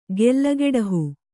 ♪ gella geḍahu